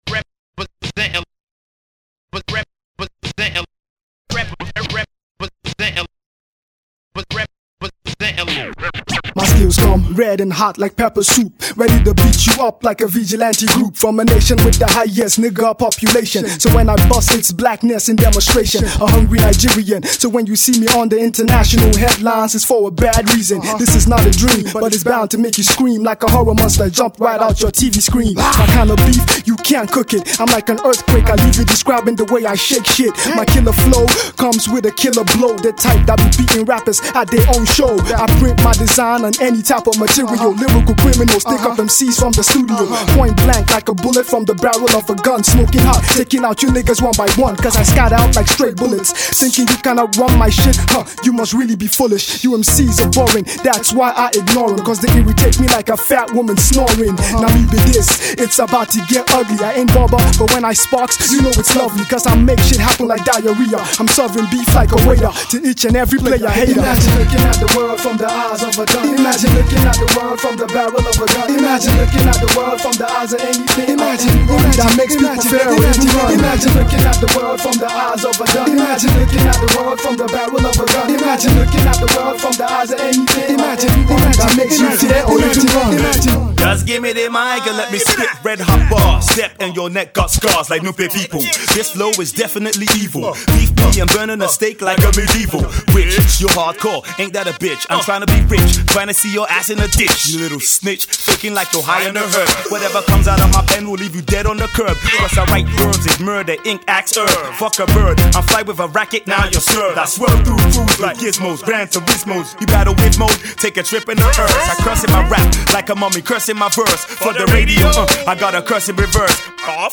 rap cut